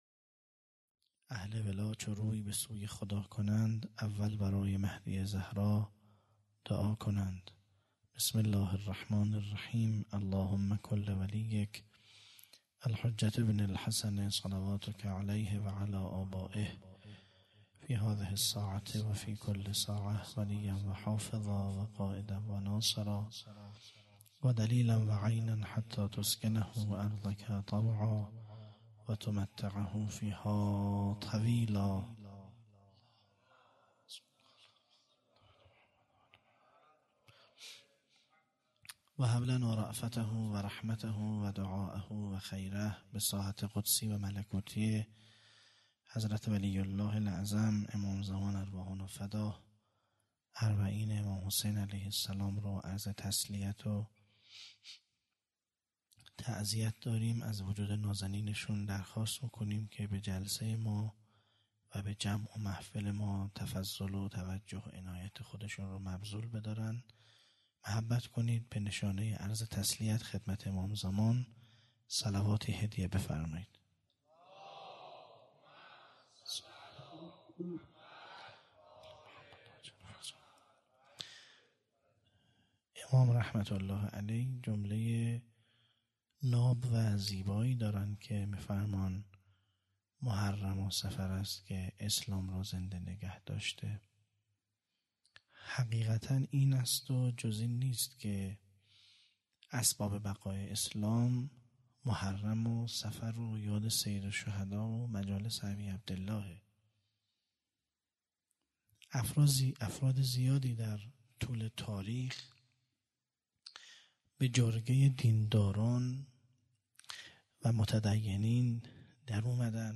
هیئت دانشجویی فاطمیون دانشگاه یزد - سخنرانی